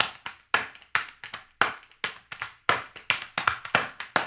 HAMBONE 09-R.wav